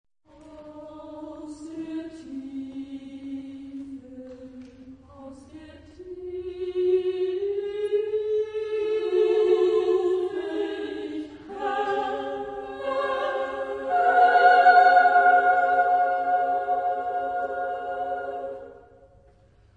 Epoque: 20th century
Genre-Style-Form: Motet ; Sacred
Type of Choir: SSSSAA  (6 women voices )
Tonality: free tonality